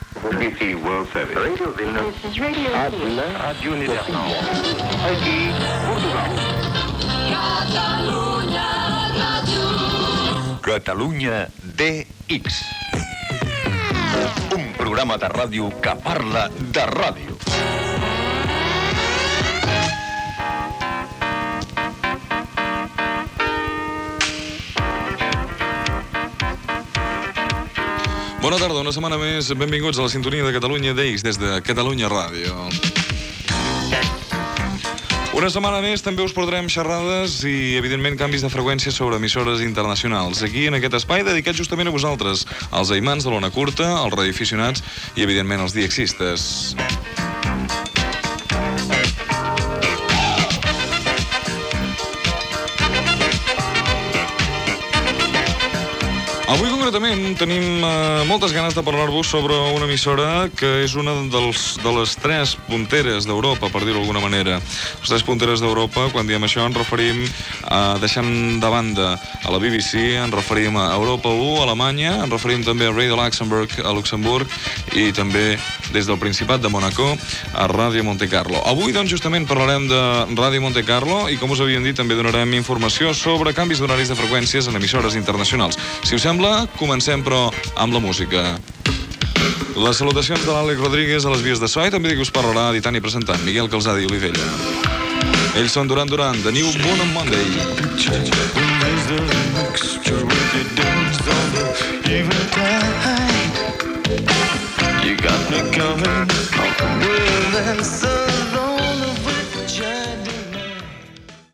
Inici del programa amb el sumari de continguts i tema musical.